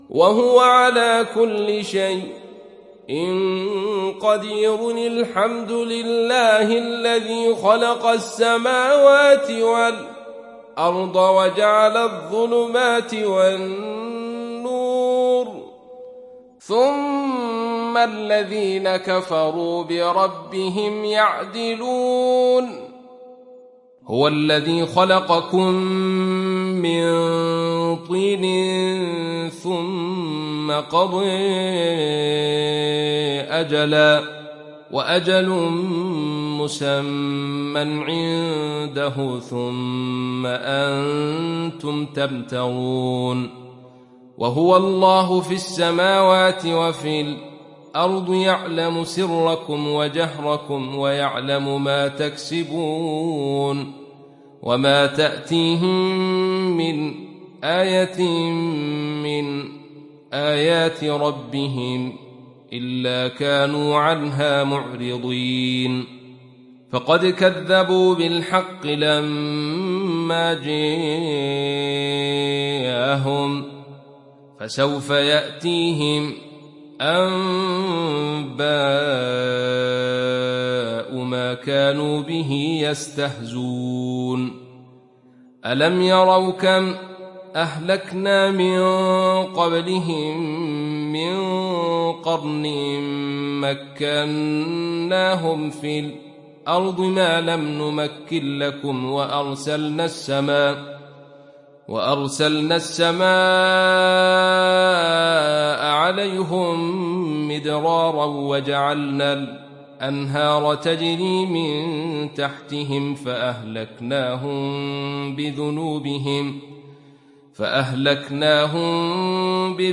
تحميل سورة الأنعام mp3 بصوت عبد الرشيد صوفي برواية خلف عن حمزة, تحميل استماع القرآن الكريم على الجوال mp3 كاملا بروابط مباشرة وسريعة